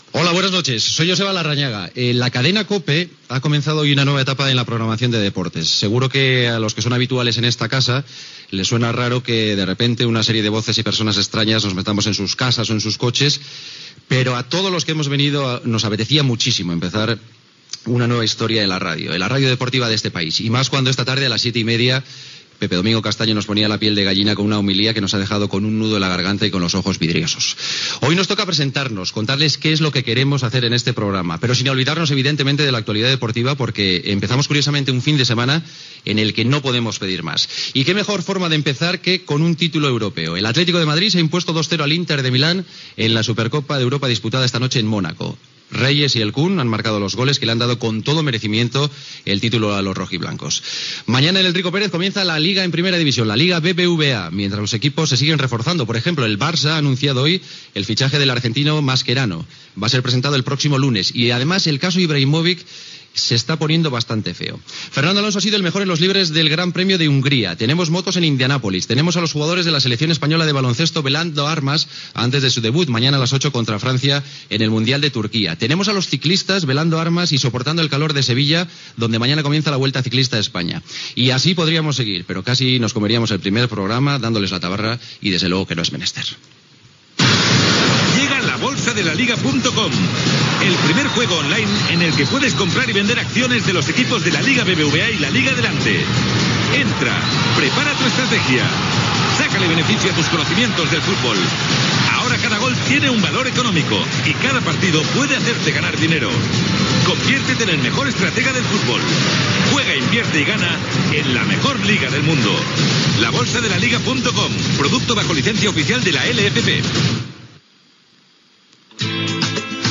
Nova programació esportiva de Cadena COPE, resum informatiu, publicitat, indicatiu i cançó del programa (interpretada i composada per Huecco (Iván Sevillano))
Esportiu
FM